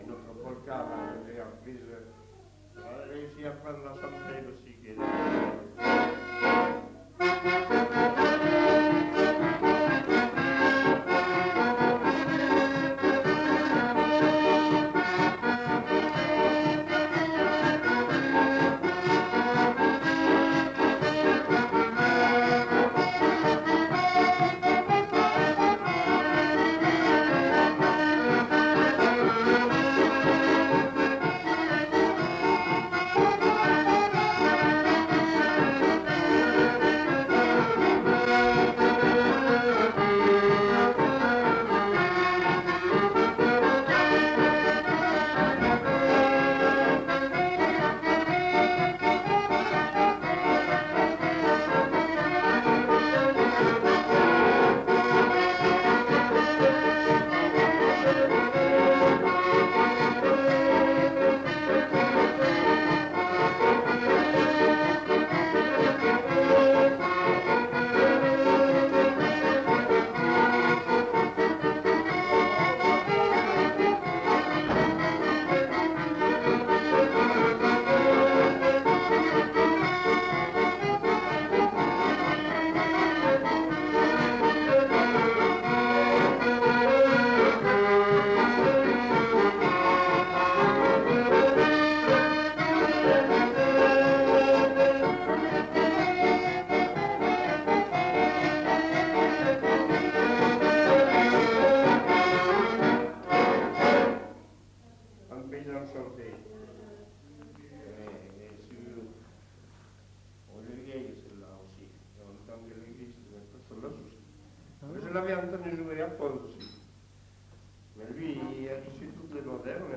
Aire culturelle : Viadène
Lieu : La Capelle (lieu-dit)
Genre : morceau instrumental
Instrument de musique : accordéon chromatique
Danse : polka